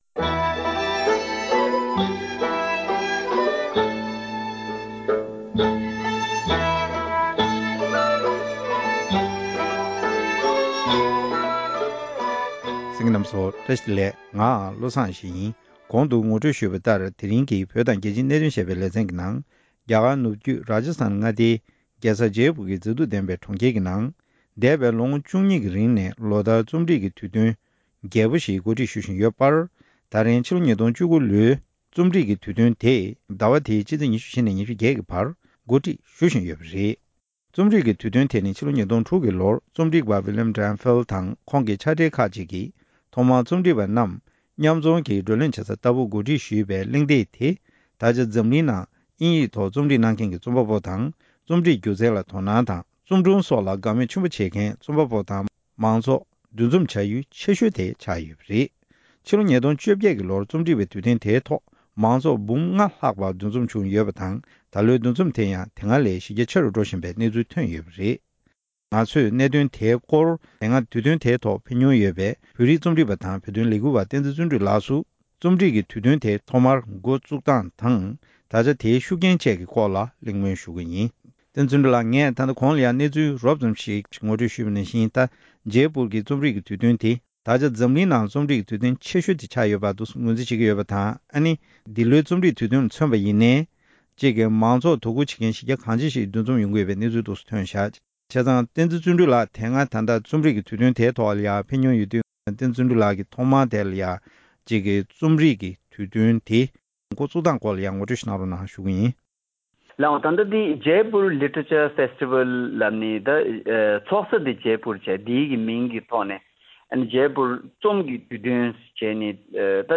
བཀའ་དྲིས་ཞུས་པ་ཞིག་གསན་རོགས་ཞུ༎